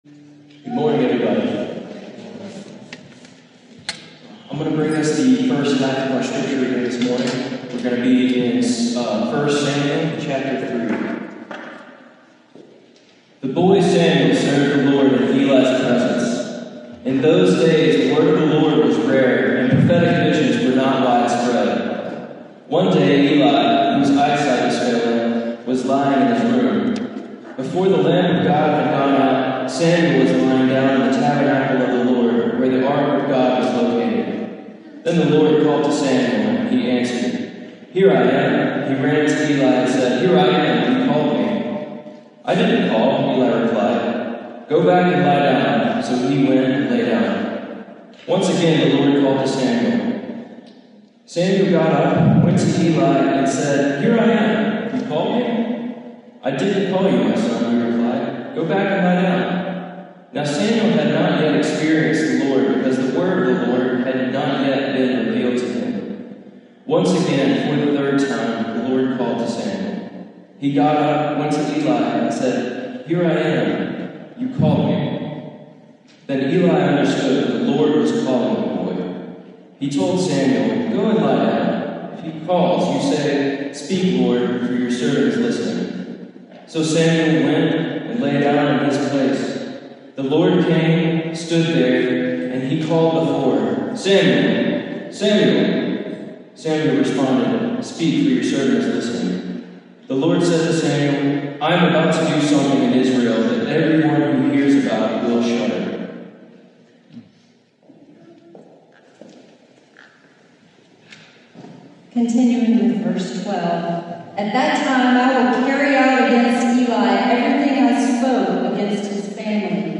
Passage: 1 Samuel 3:11-19 Service Type: Sunday Morning